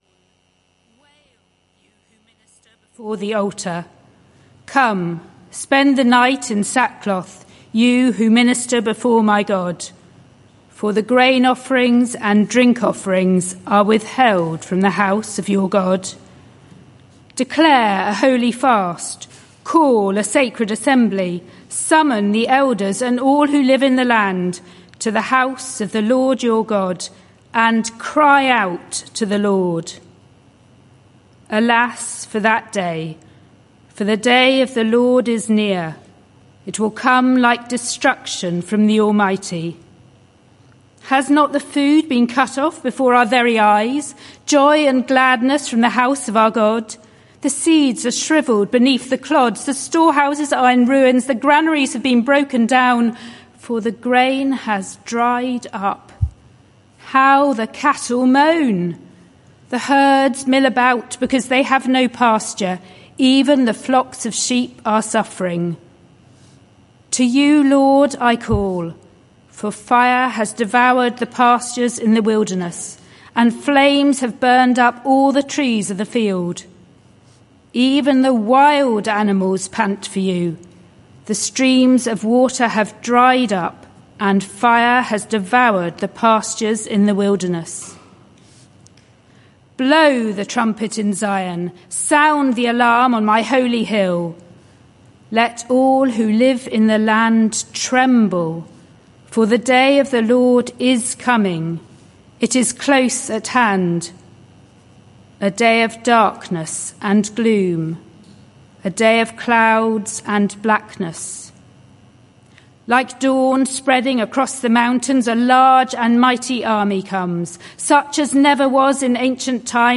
This sermon is part of a series: 12 November 2023